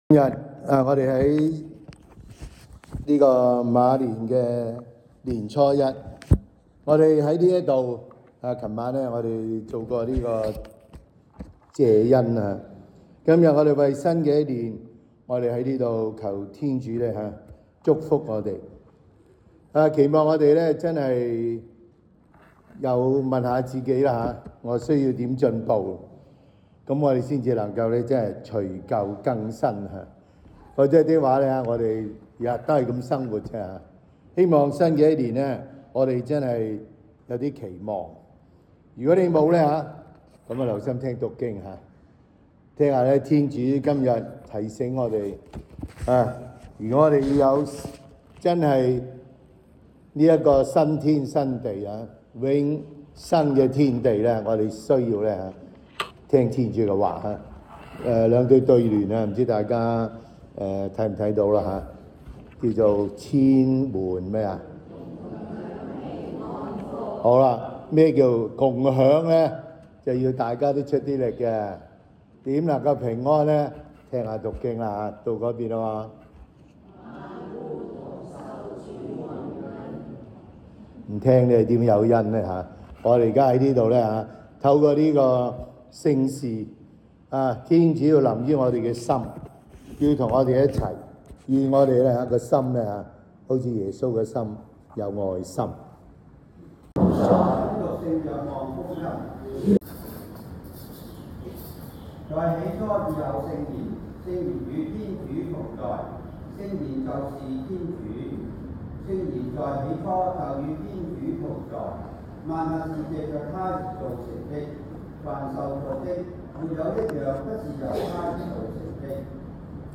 農曆新春求恩彌撒 (2026年2月17日)
SDB 每日講道及靈修講座